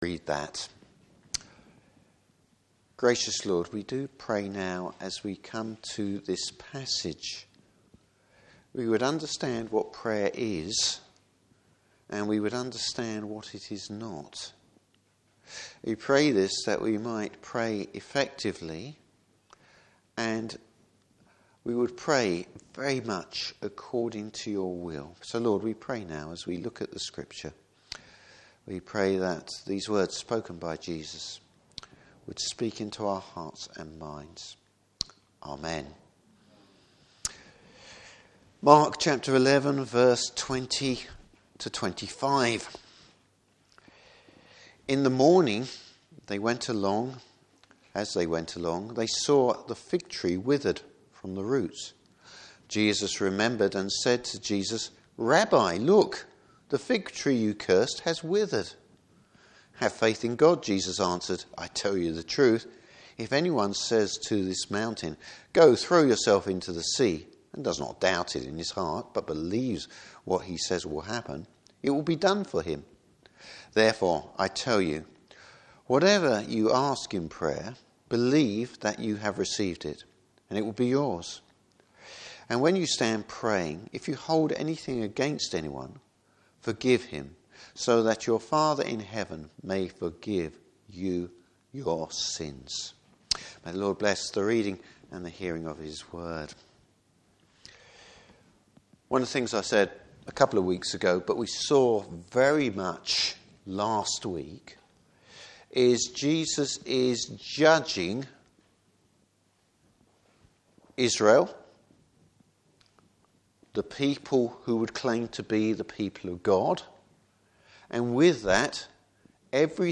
Service Type: Morning Service How faith and prayer work together.